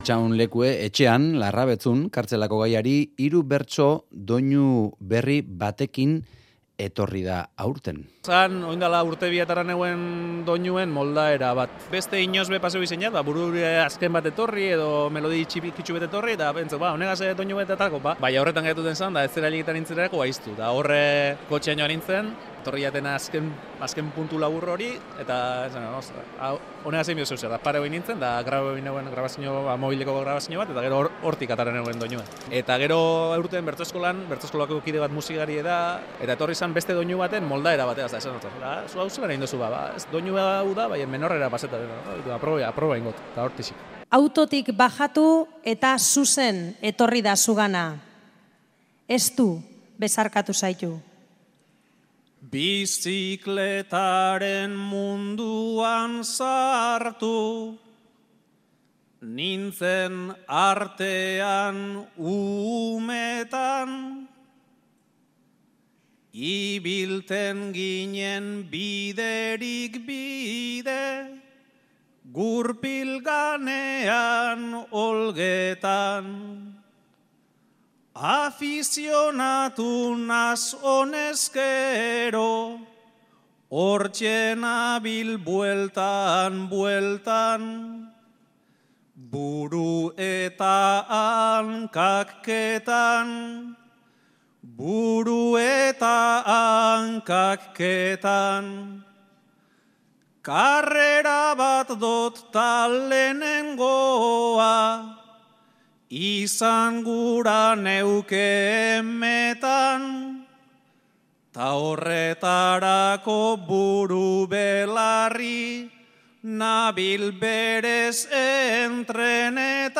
Larrabetzun doinu berria ekarri zuen oholtzara kartzelako ariketan
Txirrindulariaren peperean abestu zuen.